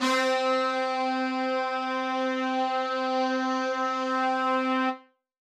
LEMONHEAD HORN.wav